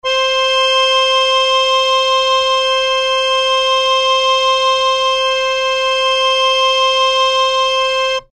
harmonium
C5.mp3